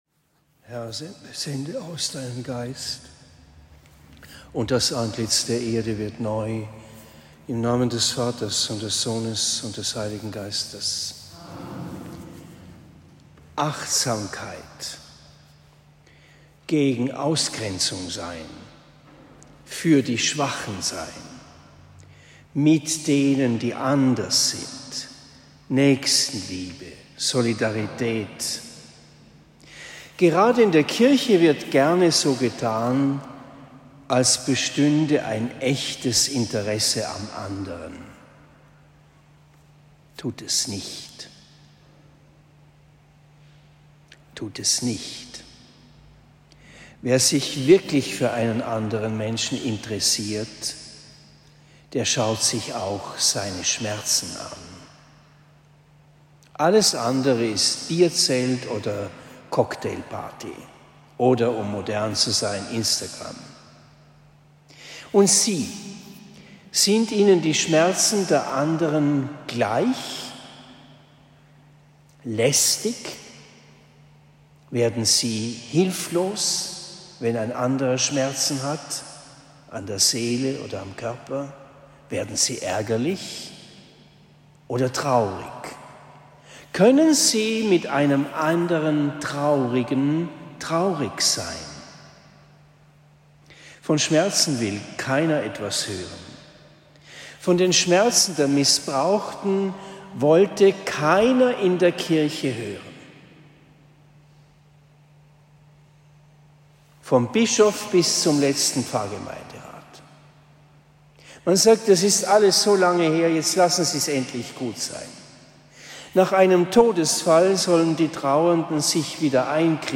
Predigt in Oberndorf am 15. September 2023